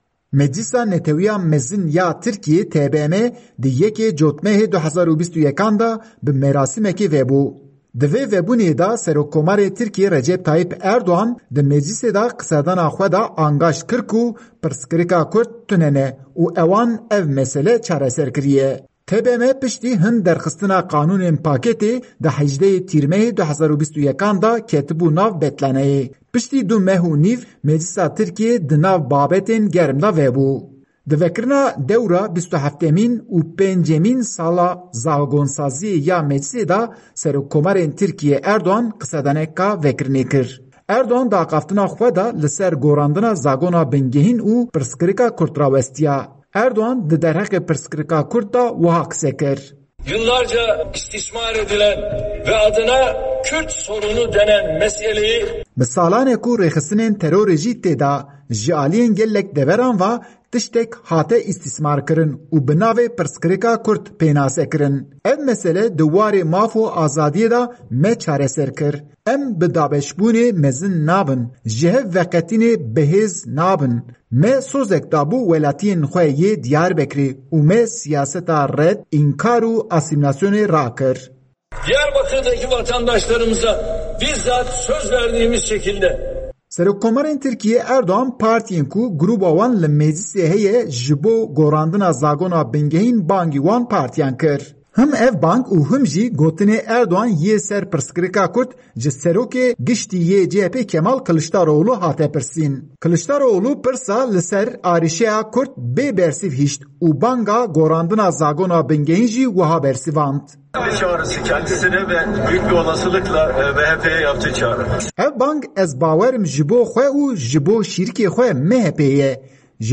Bêtir agahî di raportên peyamnêrên me ji Enqere û Diyarbekirê de.